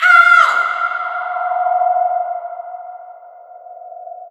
AAHH VOX 1-R.wav